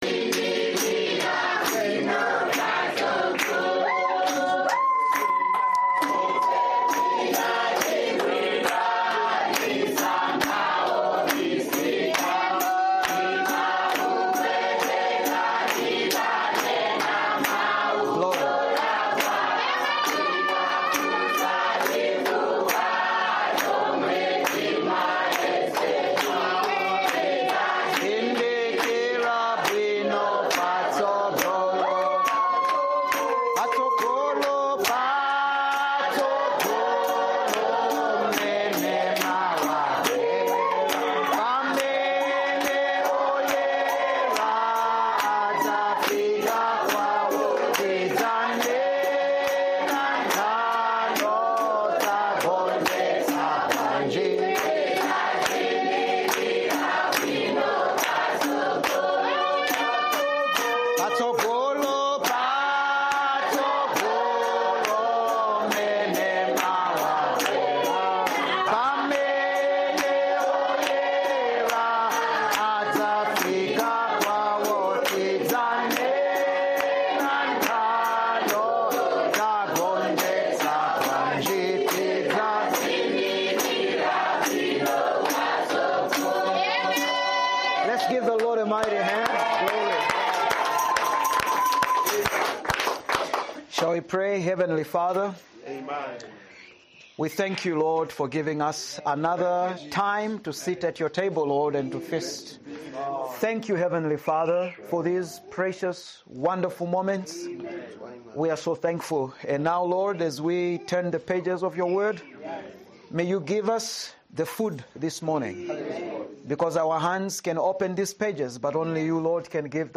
Preached in Chimoga, Malawi